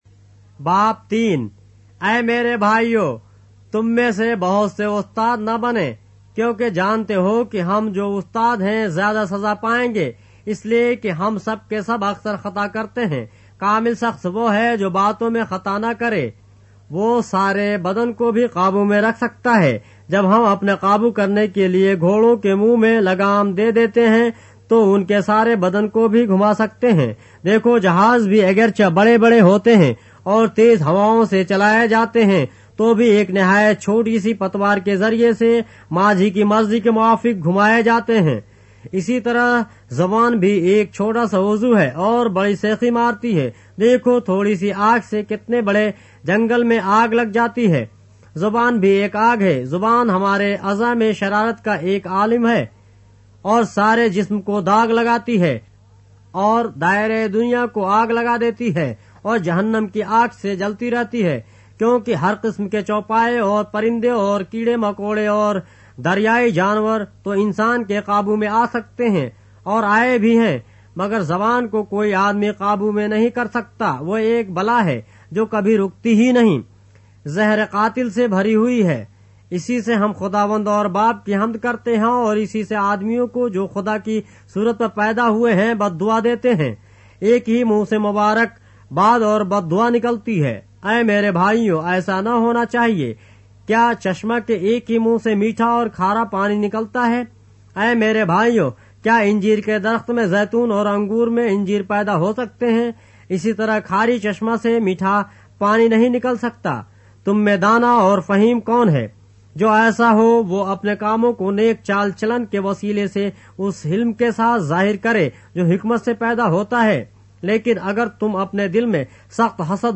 اردو بائبل کے باب - آڈیو روایت کے ساتھ - James, chapter 3 of the Holy Bible in Urdu